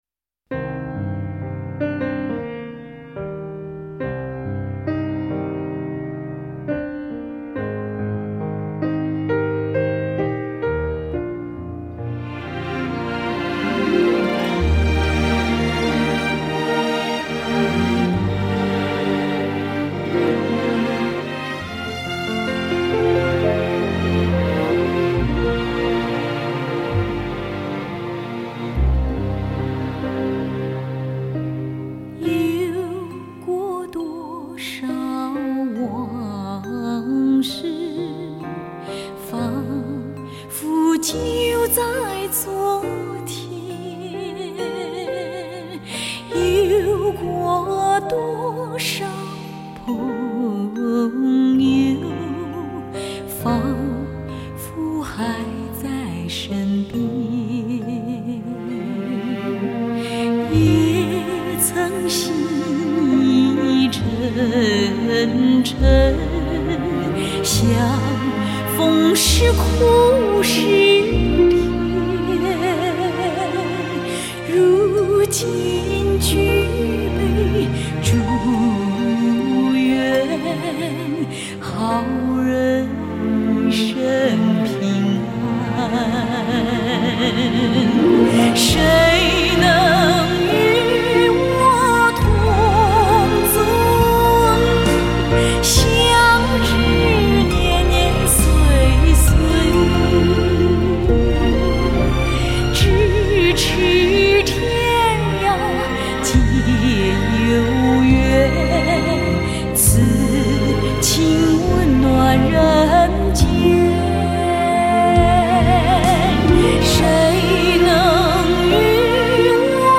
她的歌声高昂激越、委婉深沉、风趣诙谐，还透着质朴，犹如她的为人。
此系列唱片为历史的录音技术模拟录音，为尊重当年录音的品质，不修饰 不增加声音内容 力求做到全部原音重现。